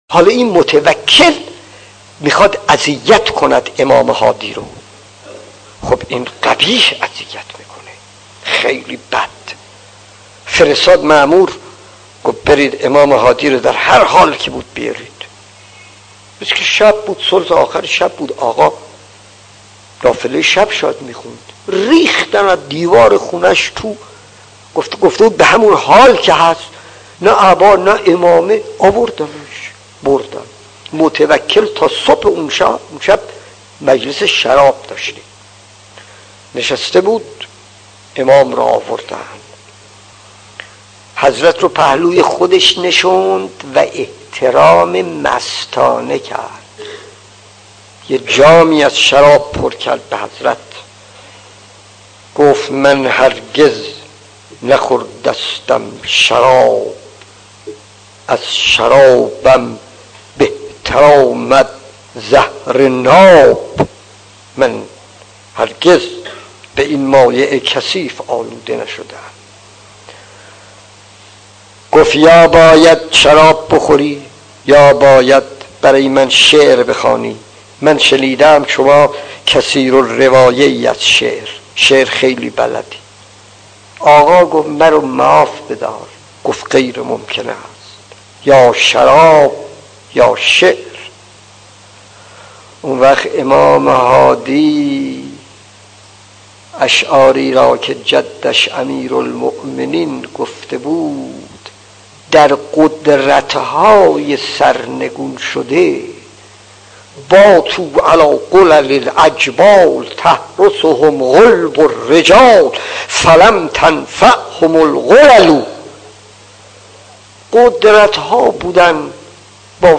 داستان 36 : امام هادی و متوکل 1 خطیب: استاد فلسفی مدت زمان: 00:05:15